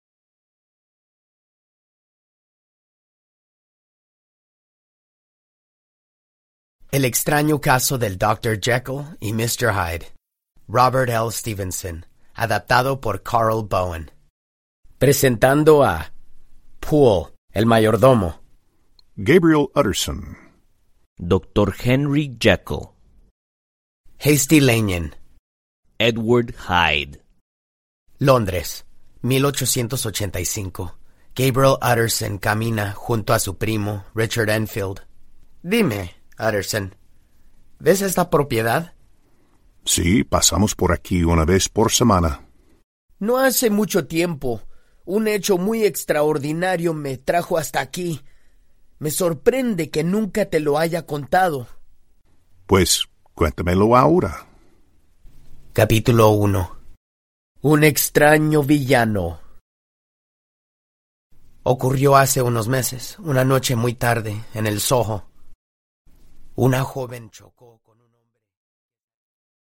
Formato Audiolibro